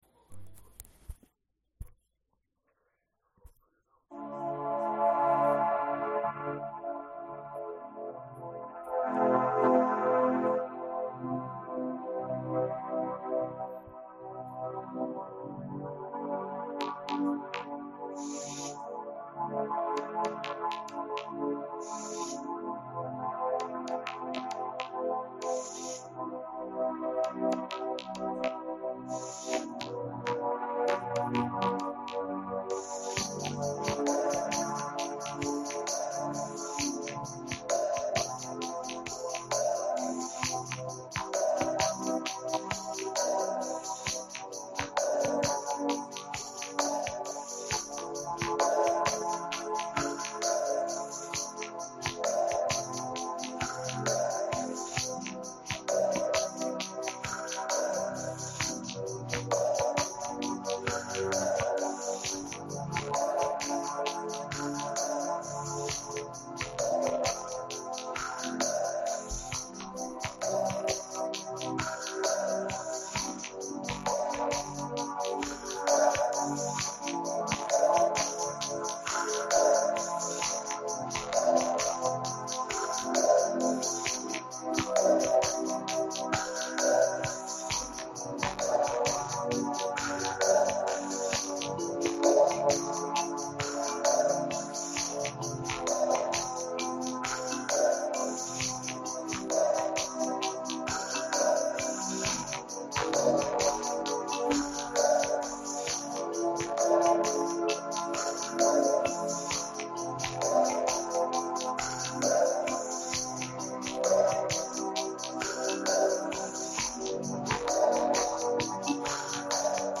in speed 0,65